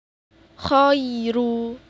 cth ro tarqiq.wav